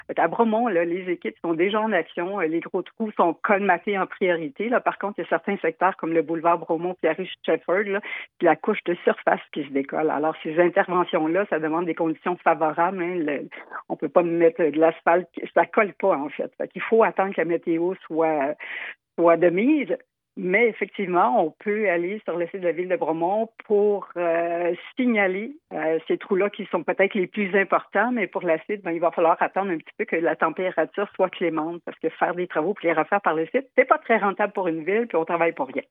De passage à M105 ce matin, la mairesse Michelle Champagne affirmait que la Ville est bien au fait du dossier et qu’elle y travaille déjà.